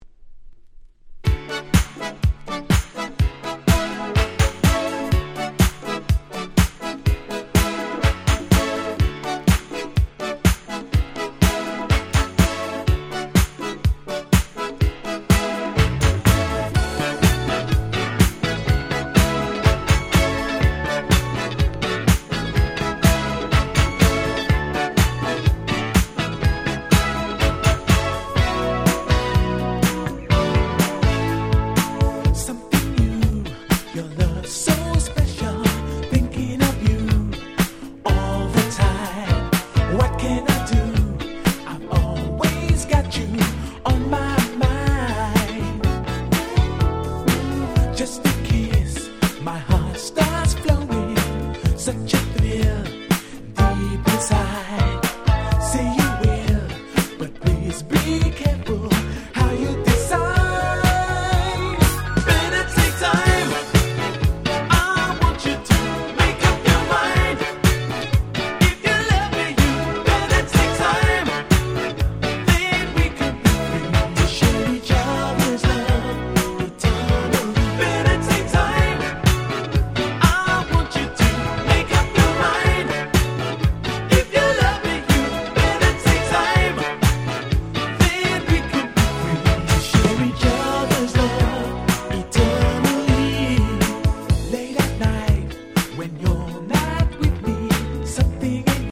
83' Nice UK Disco / Boogie !!
セカンドイメージ ディスコ ブギー